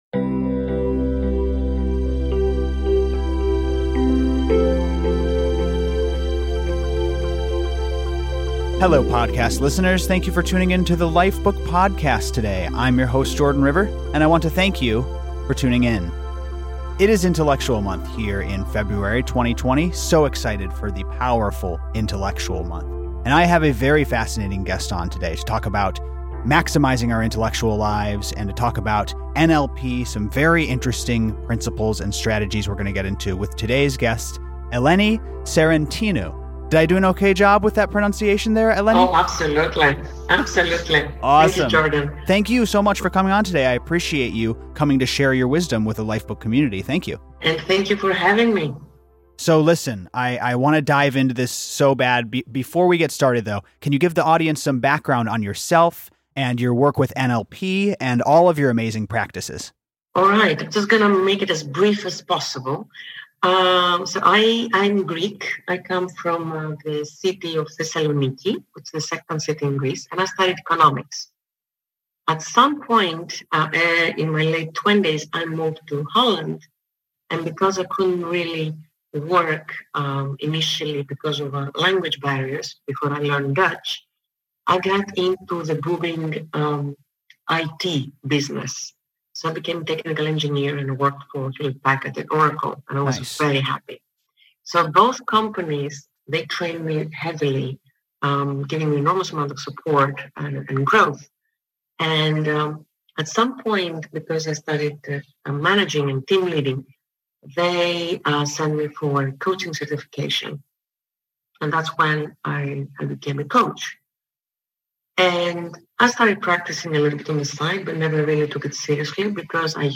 Expert Interview